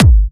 VEC3 Bassdrums Trance 26.wav